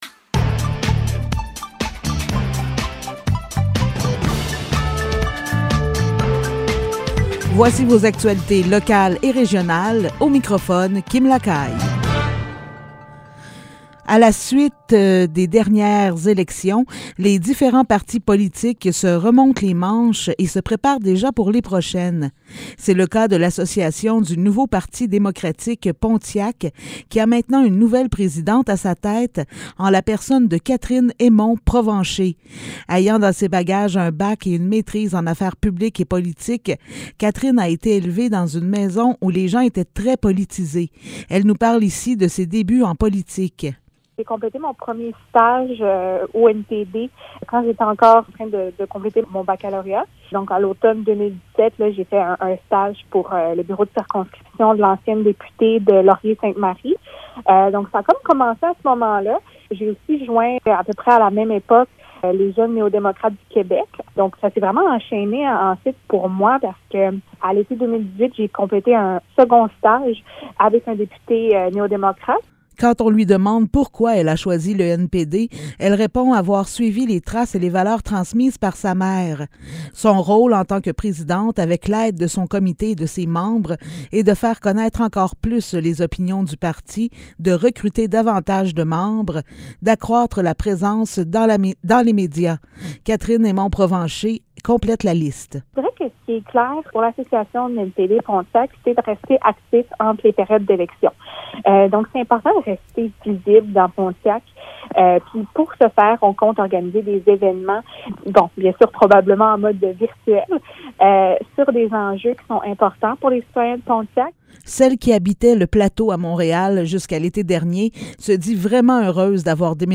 Nouvelles locales - 22 décembre 2021 - 15 h